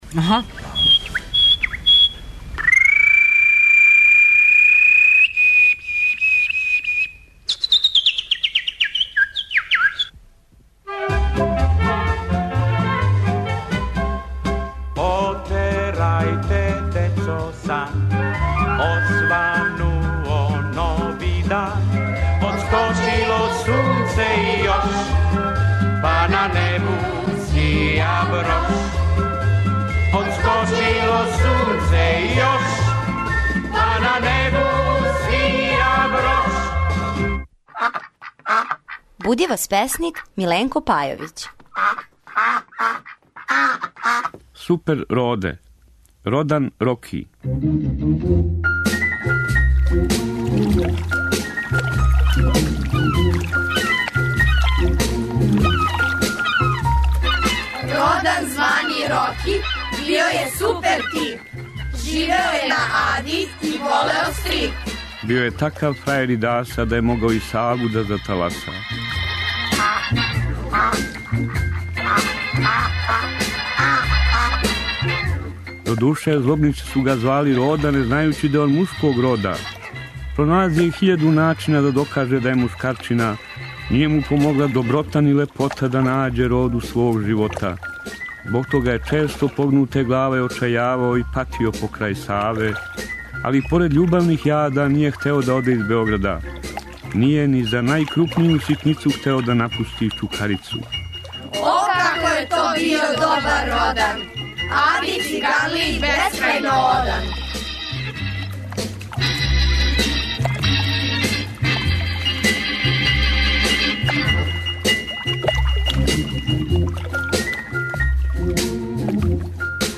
Прича за добро јутро